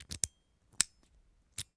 Lock Pad Open Key Sound
household
Lock Pad Open Key